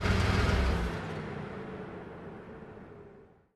CosmicRageSounds / ogg / general / cars / shutdown_out.ogg
shutdown_out.ogg